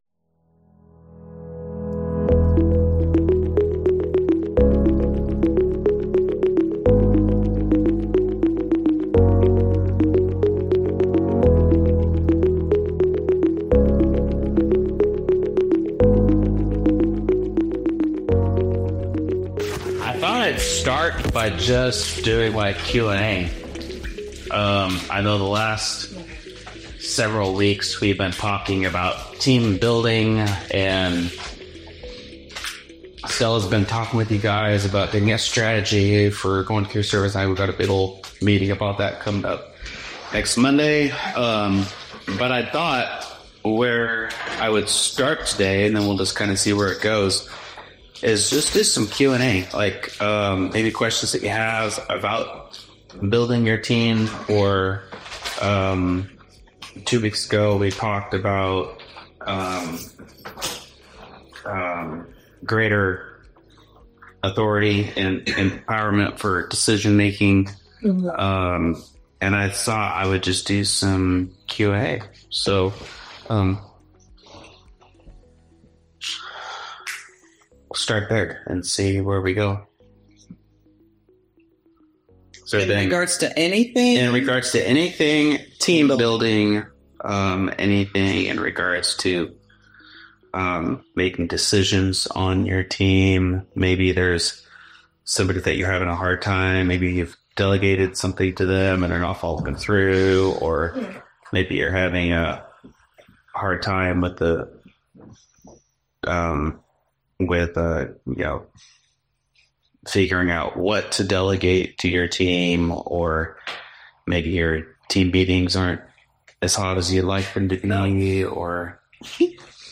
In this special Q&A episode, we tackle your most pressing leadership questions. From building strong teams and casting vision, to navigating challenges and staying spiritually grounded, we dive into practical advice and insights for leaders at every level.